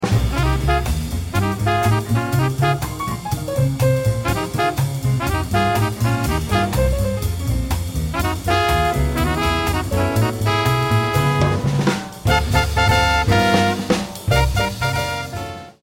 A hard swinging, peppy medium-up piece.
These sample audio clips start with the melody.